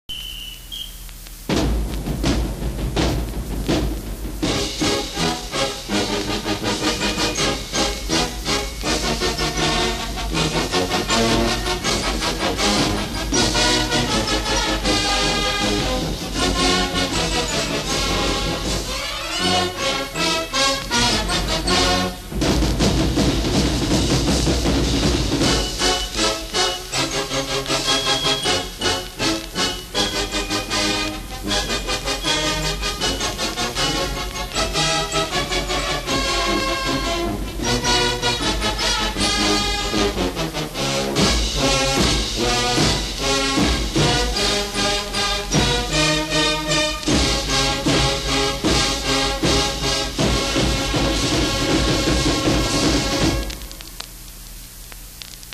MCC Marching Band